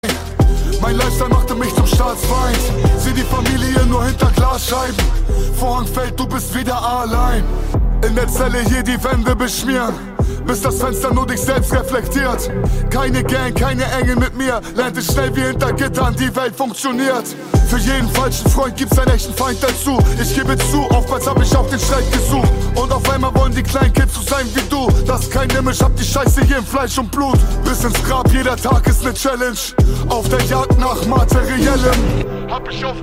Kategorien: Rap/Hip Hop